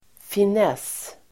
Uttal: [fin'es:]